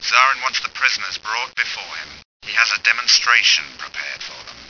―Imperial comm message during the attack on Pondut — (audio)